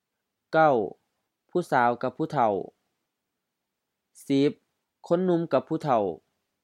พุสาว phu-sa:o H-M เจ้าสาว 1. girl, young lady
พุเถ้า phu-thao H-LF ผู้เฒ่า old person